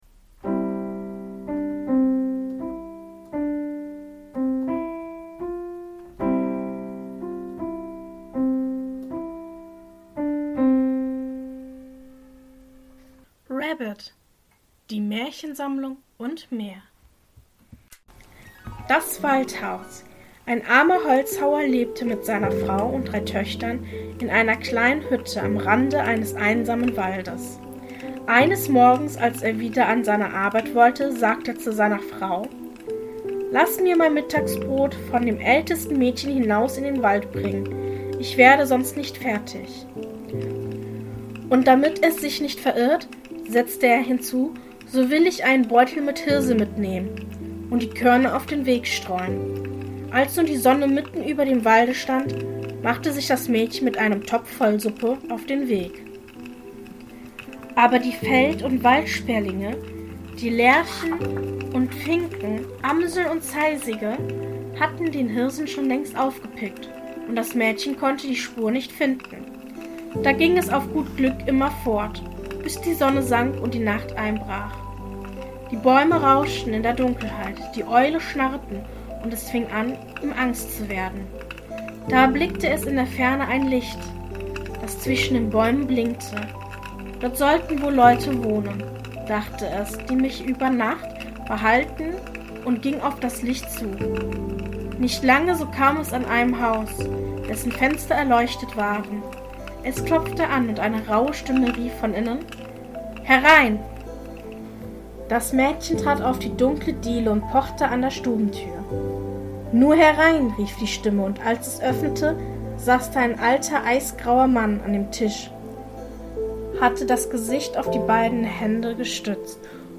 In der heutigen Folge lese ich Folgendes vor: 1. Das Waldhaus 2. Das Schlaraffenland 3. König Drosselbart Mehr